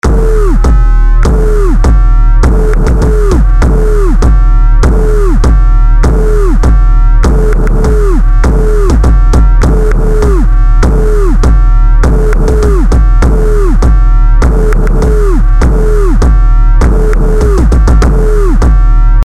Etwas düster und destruktiv, zusammen mit einer zweiten PunchBox: